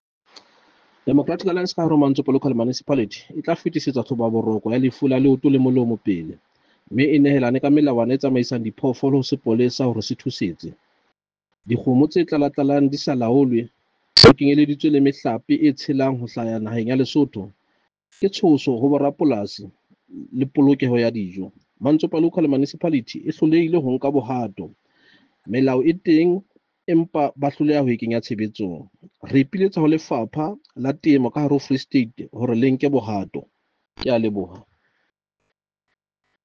Sesotho soundbite by Cllr Tim Mpakathe.